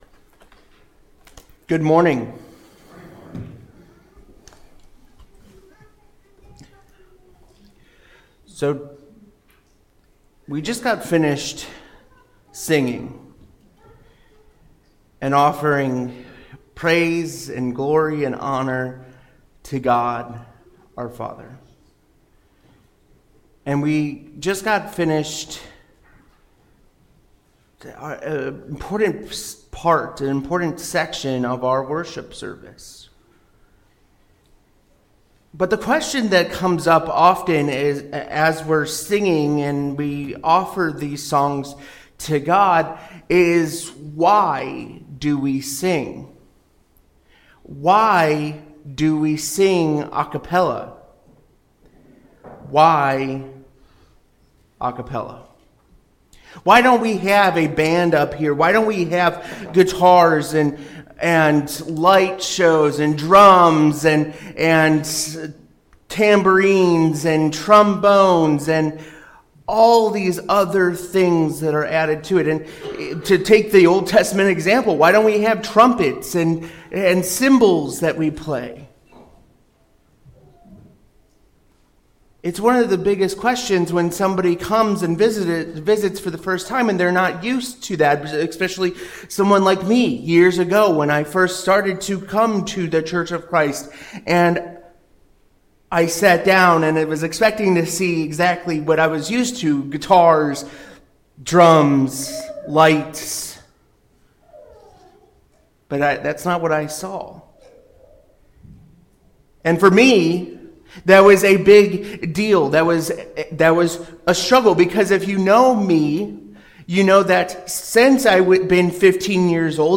Sunday Sermons Why A Cappella?